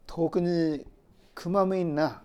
Aizu Dialect Database
Type: Yes/no question(?)
Final intonation: Falling
Location: Showamura/昭和村
Sex: Male